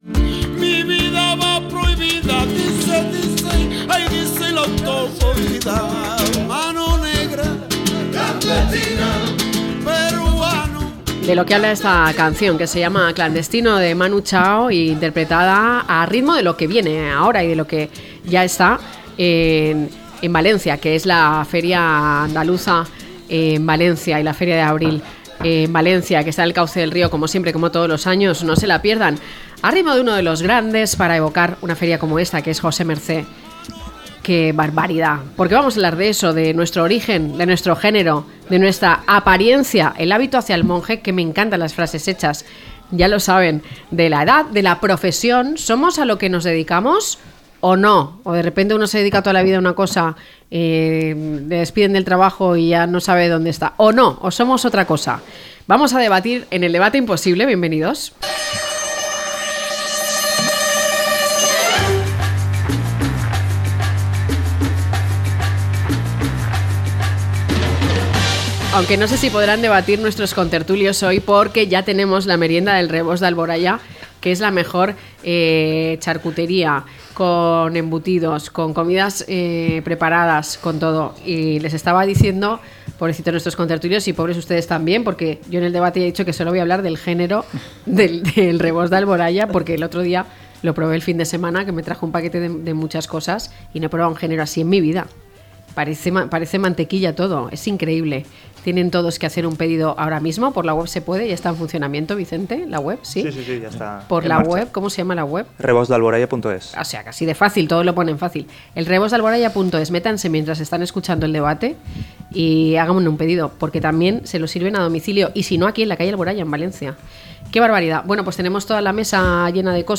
Estereotipos y clichés, a debate - La tarde con Marina